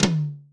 Royality free tom drum single hit tuned to the F note. Loudest frequency: 1552Hz
• Tom Drum Single Hit F Key 18.wav
tom-drum-single-hit-f-key-18-do3.wav